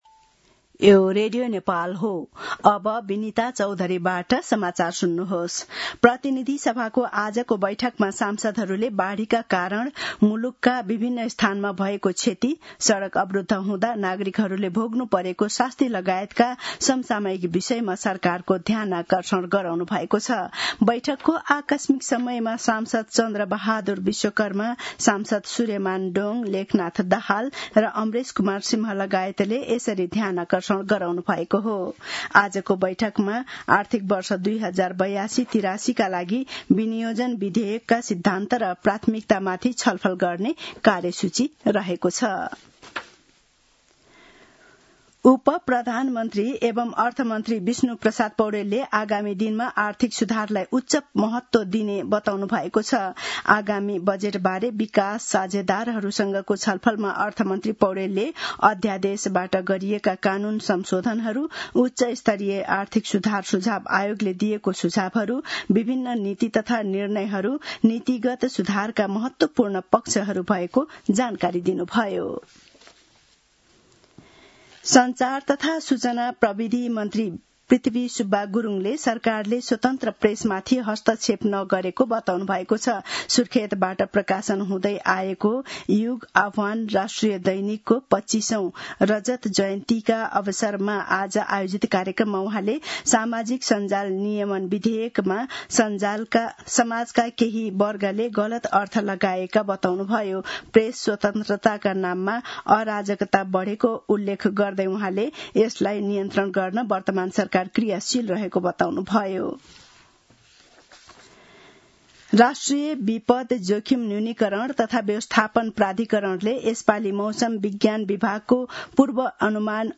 दिउँसो १ बजेको नेपाली समाचार : ३० वैशाख , २०८२
1-pm-news-1-1.mp3